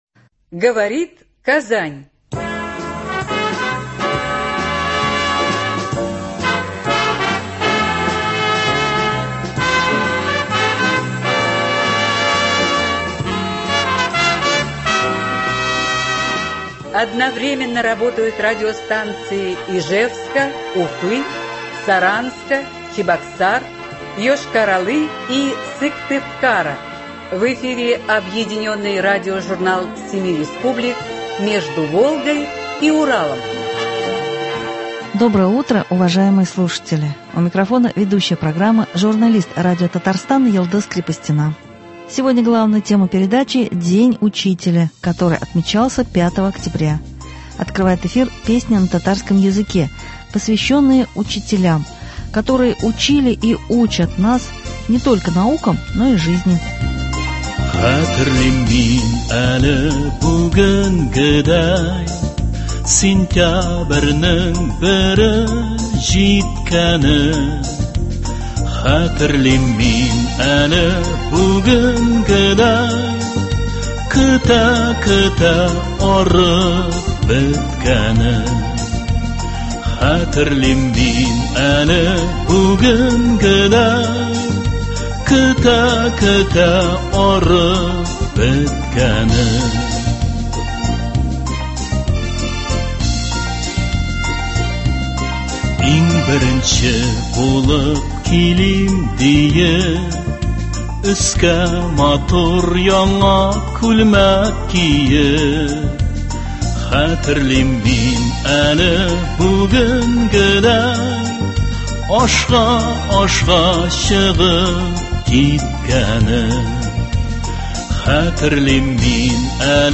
Объединенный радиожурнал семи республик.
Сегодня главная тема передачи – День учителя, который отмечался 5 октября. Открывает эфир песня на татарском языке, посвященная учителям, которые учили и учат всех нас не только наукам, но и жизни.